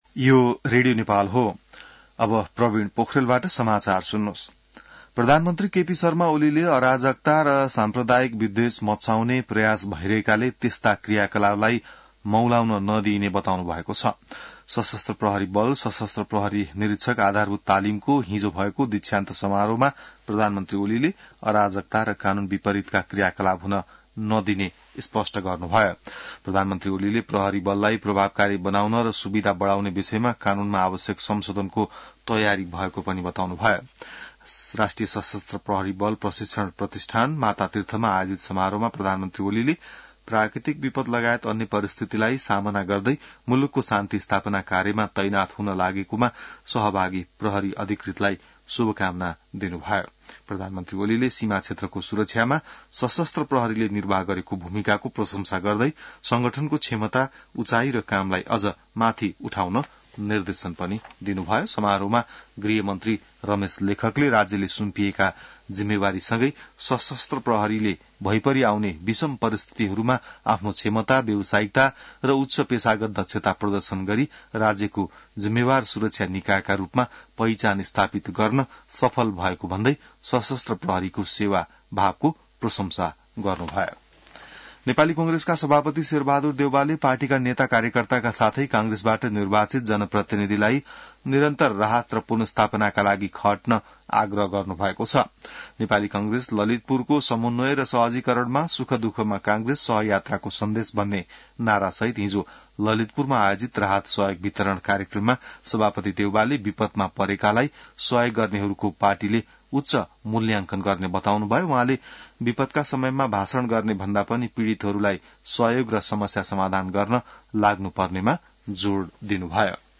An online outlet of Nepal's national radio broadcaster
बिहान ६ बजेको नेपाली समाचार : १५ कार्तिक , २०८१